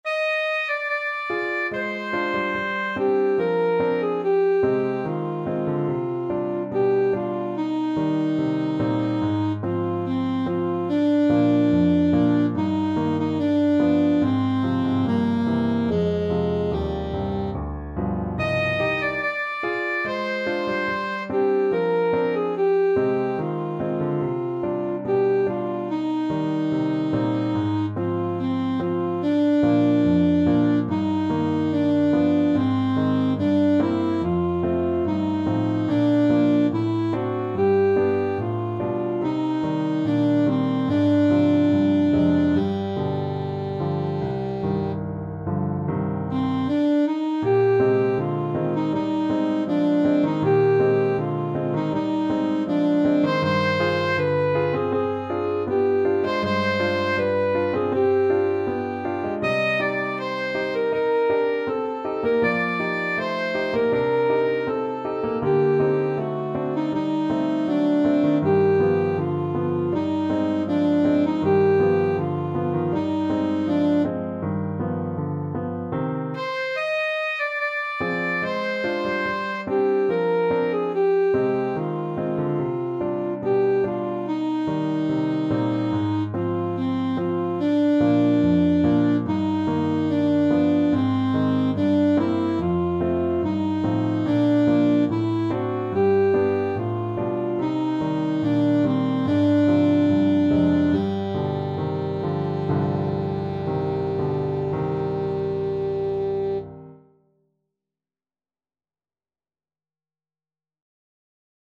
Alto Saxophone
2/4 (View more 2/4 Music)
~ = 72 Andantino (View more music marked Andantino)
Classical (View more Classical Saxophone Music)